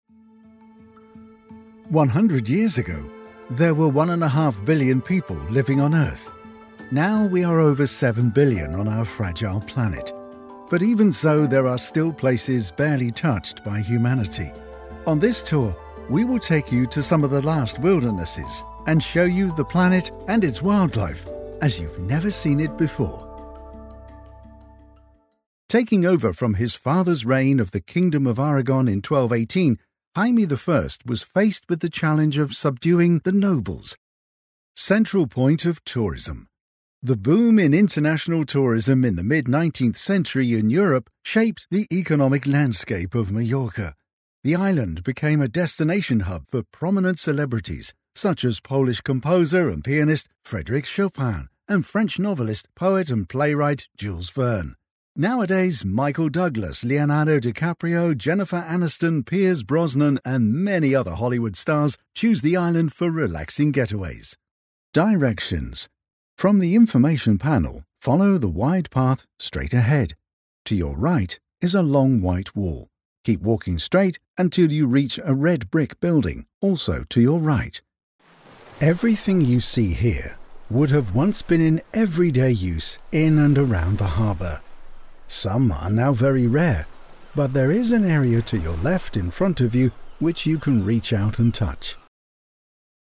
Englisch (Britisch)
Vielseitig, Zuverlässig, Corporate, Erwachsene, Natürlich
Audioguide